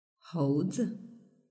hóu zi